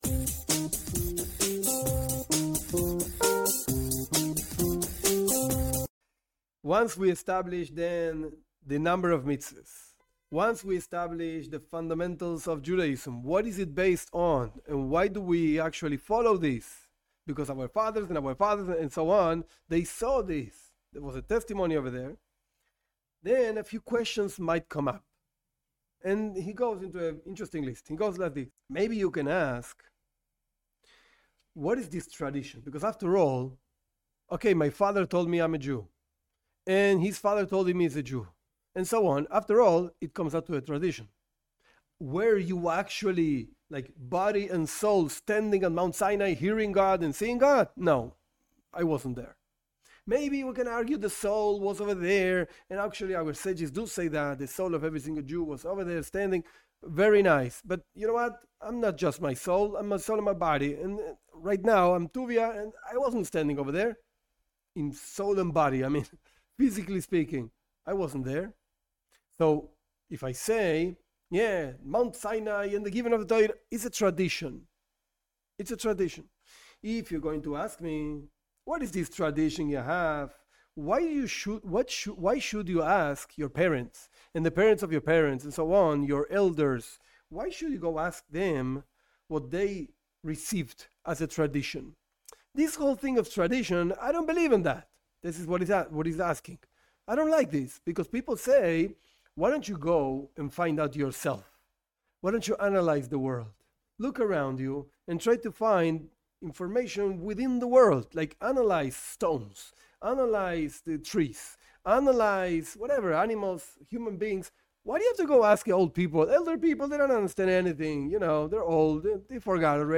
This class explains parts of the introduction of a classic Jewish book: The Sefer HaChinuch, the Book of Education. With this introduction we can understand the basic ideas of Judaism and the message for non Jews also.